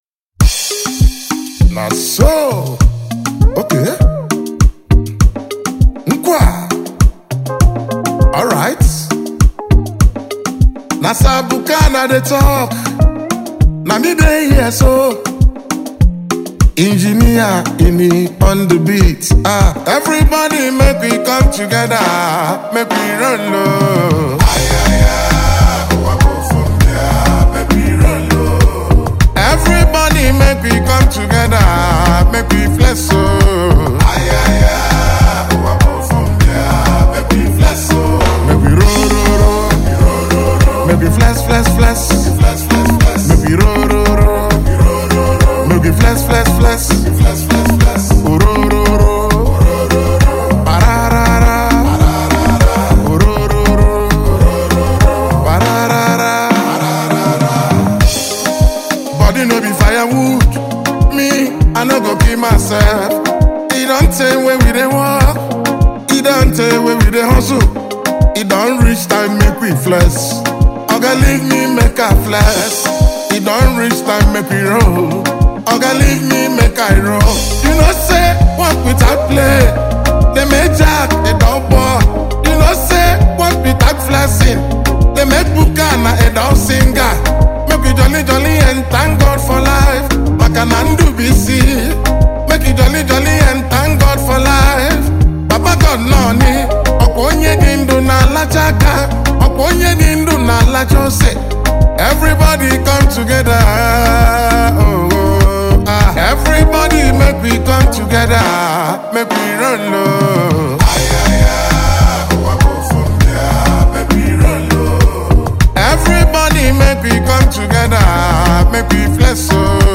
A mid tempo hylife Hip-hop Hit